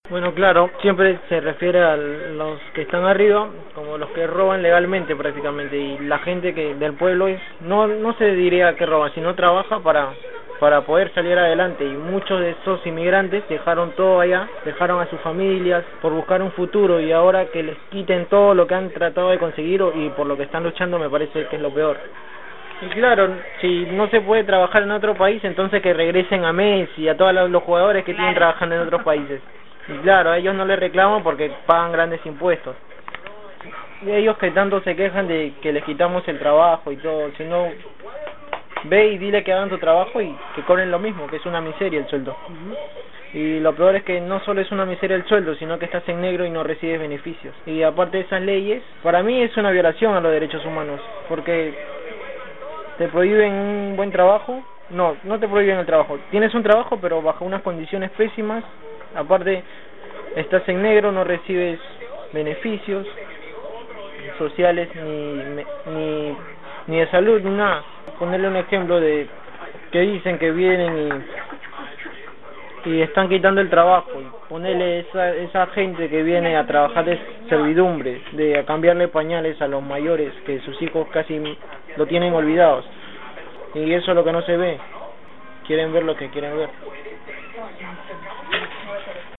Las voces que podés escuchar más abajo son de mujeres y jóvenes migrantes del Barrio JL Cabezas de La Plata, uno de los más castigados por la inundación del año pasado.